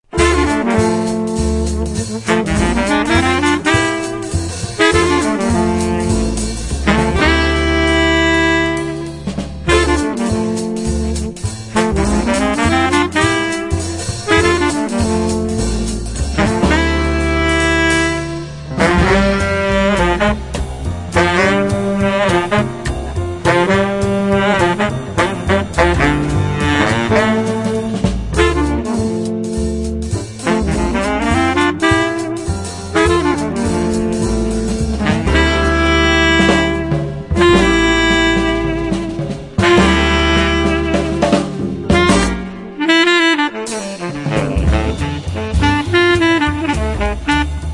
The Best In British Jazz
Recorded Red Gables Studios, December 13th 2006
Fellow multi reed man